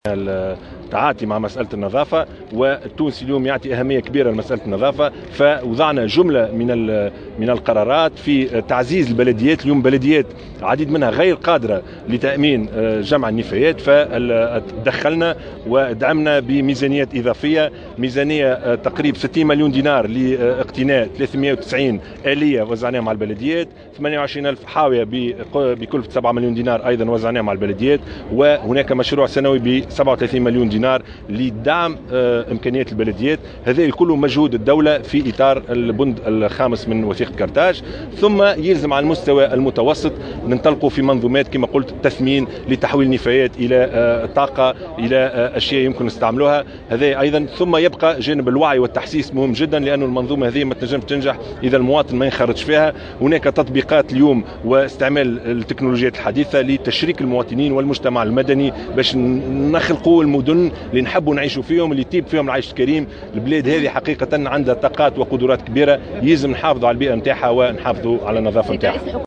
قال رئيس الحكومة يوسف الشاهد اليوم الثلاثاء، إن حكومته تسعى "لخلق مدن يطيب فيها العيش"، بحسب تعبيره في تصريحات اليوم الثلاثاء على هامش الاعلان رسميا اليوم عن اطلاق "الشرطة البيئية".